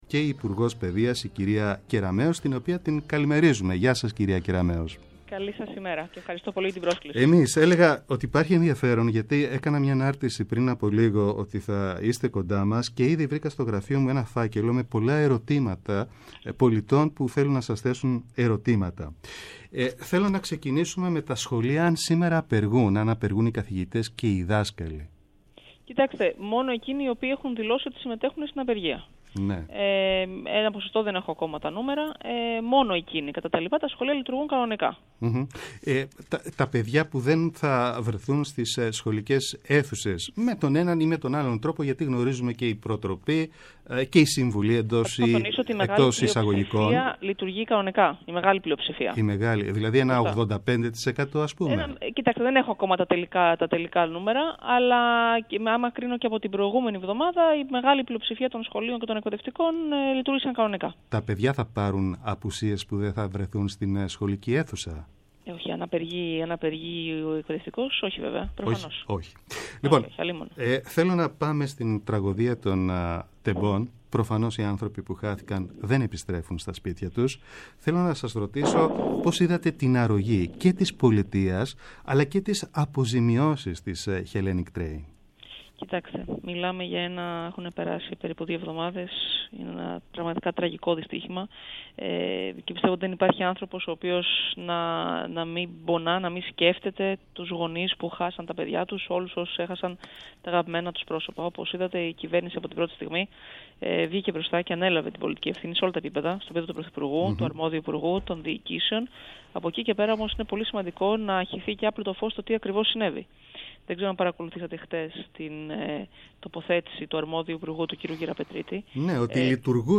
Η Υπουργός Παιδείας Νίκη Κεραμέως στο Πρώτο Πρόγραμμα | 16.03.23